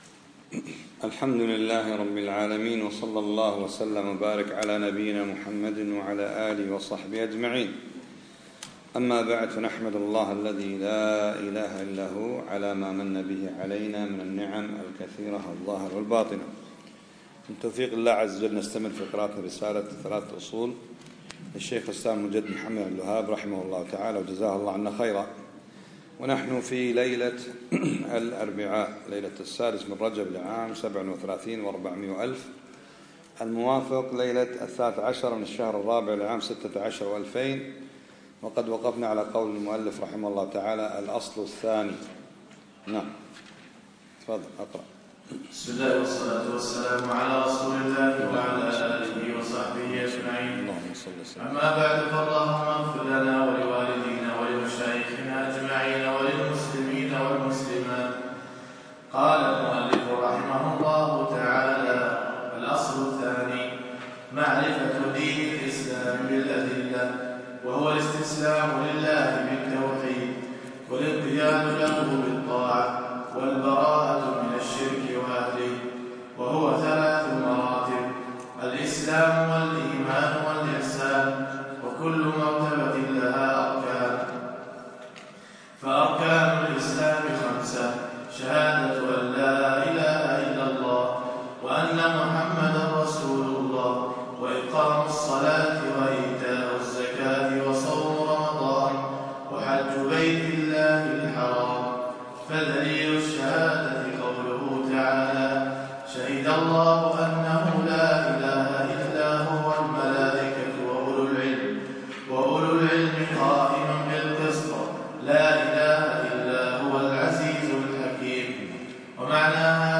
الدرس الثامن